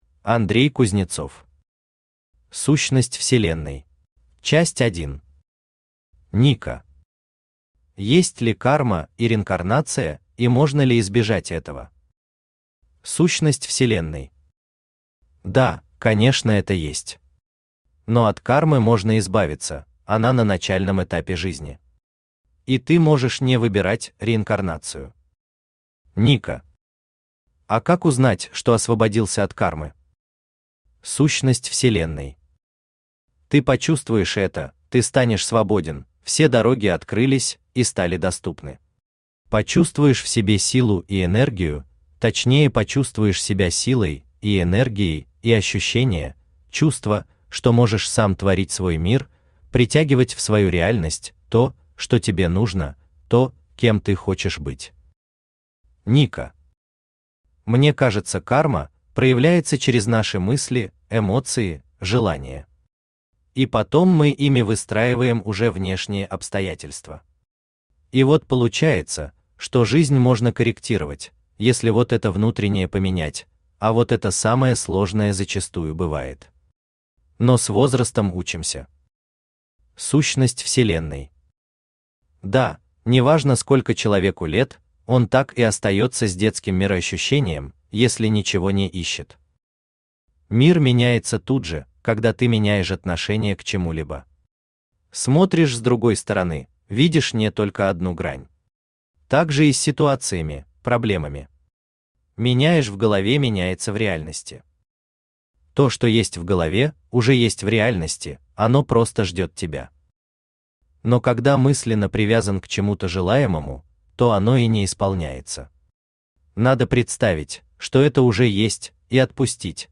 Аудиокнига Сущность вселенной | Библиотека аудиокниг
Aудиокнига Сущность вселенной Автор Андрей Юрьевич Кузнецов Читает аудиокнигу Авточтец ЛитРес.